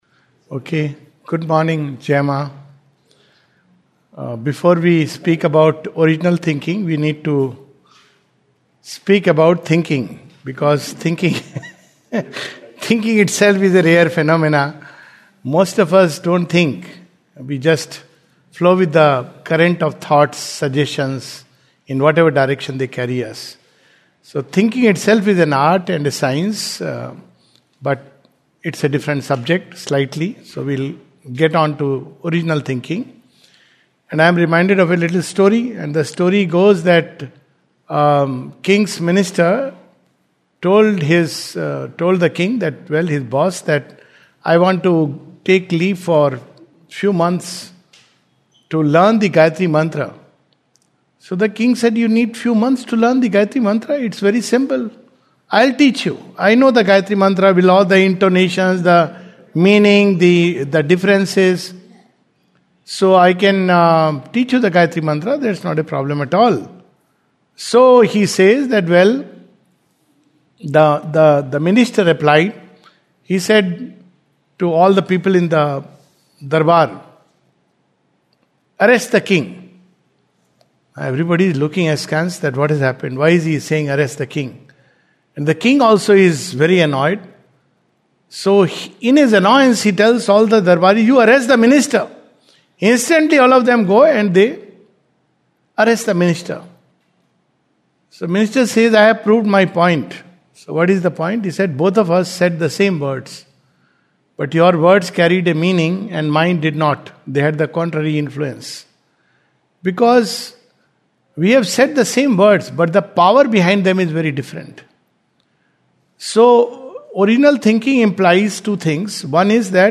a short reflection in English